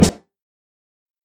Snare (Puppet).wav